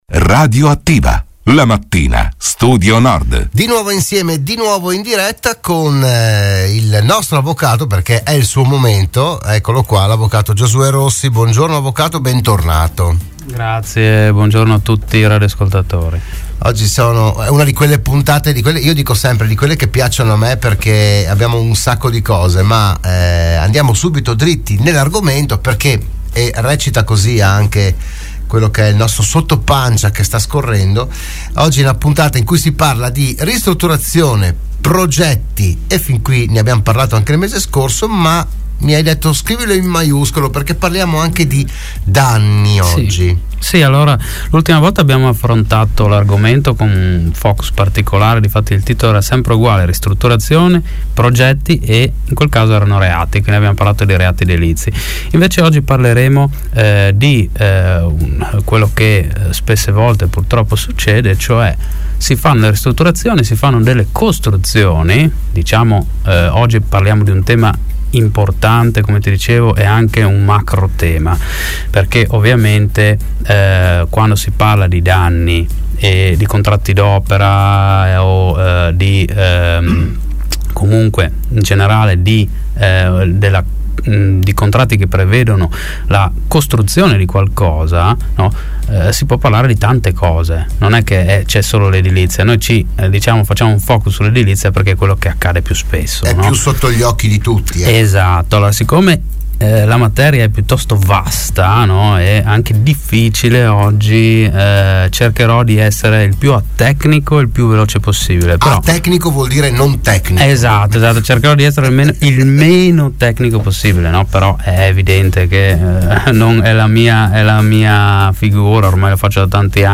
Si chiama “Il Diritto in diretta” lo spazio dedicato all’approfondimento e alla divulgazione della cultura giuridica previsto all’interno della trasmissione di Radio Studio Nord “RadioAttiva”